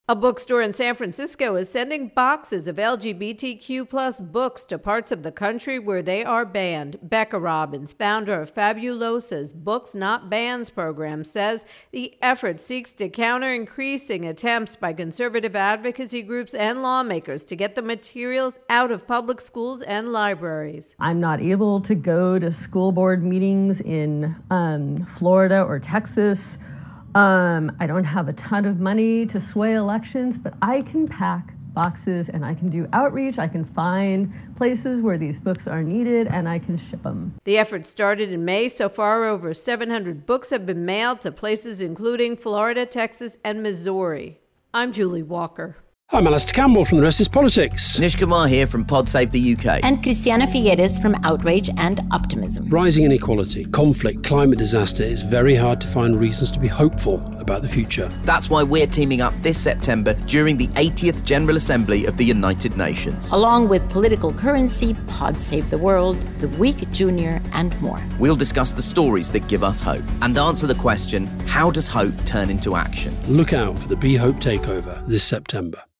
reports on how LGBTQ+ books are making their way to states where they are banned.